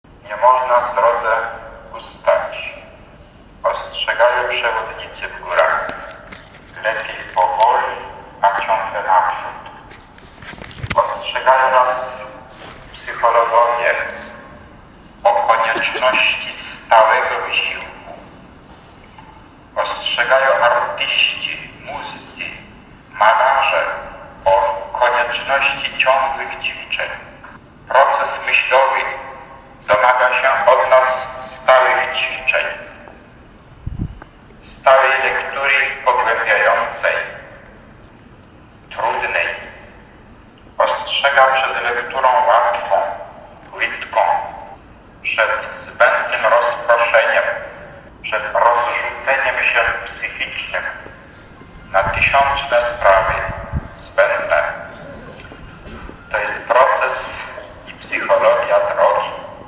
W czasie drogi krzyżowej można było usłyszeć archiwalne nagrania z oryginalnym głosem samego kard. Wyszyńskiego.
Prezentujemy fragmenty wypowiedzi kard. Stefana Wyszyńskiego: